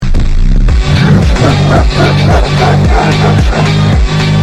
Evil Laugh Sound Effect Free Download
Evil Laugh